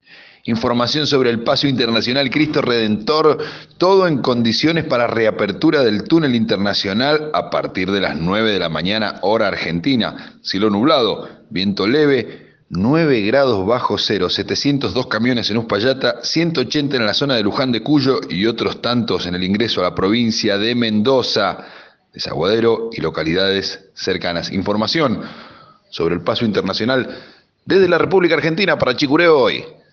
CH H Informa desde Mendoza